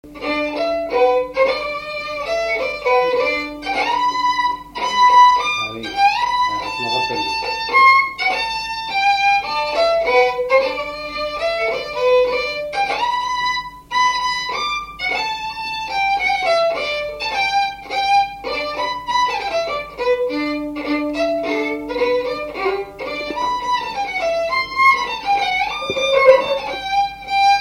Marche
Mouchamps
Résumé instrumental
gestuel : à marcher
Pièce musicale inédite